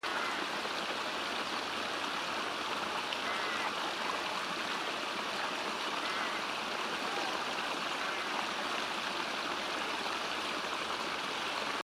So they are a bit rough and have extra junk in them and are sometimes a bit noisy. (Camera whine.)